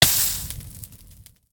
fire_hurt1.ogg